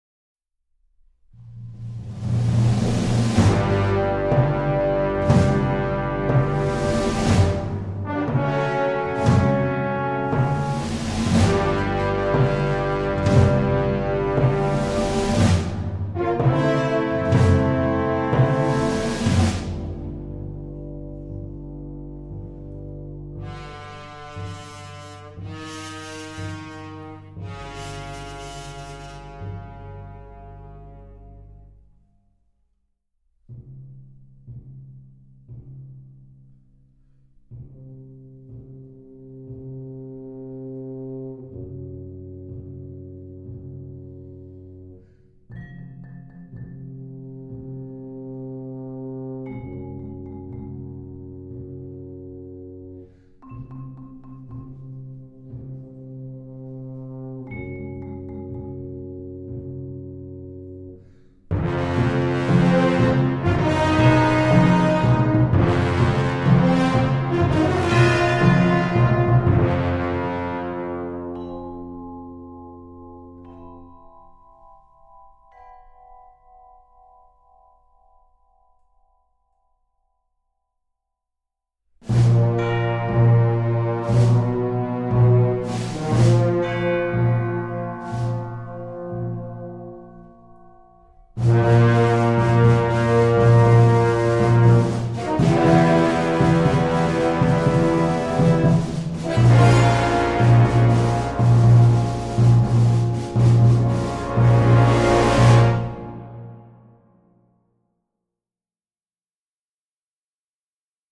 Partitions chambristes, brillamment reconstituées.